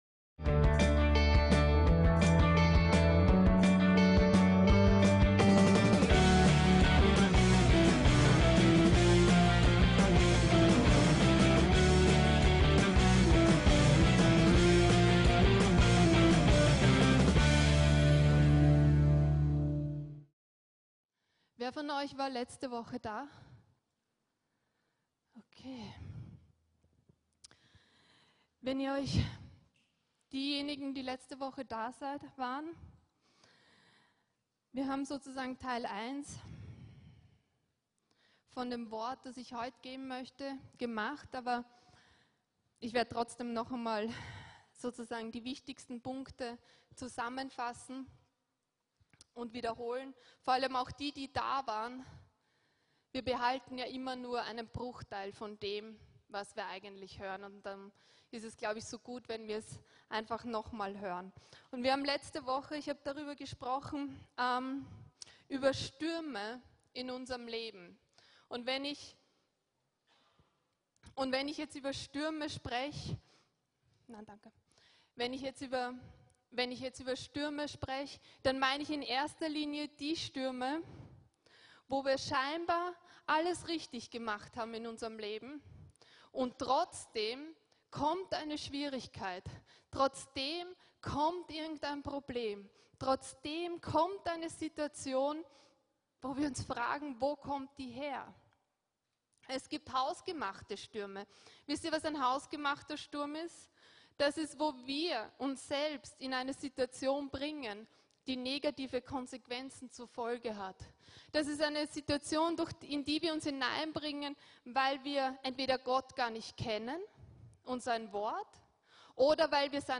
VCC JesusZentrum Gottesdienste